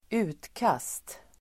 Uttal: [²'u:tkas:t]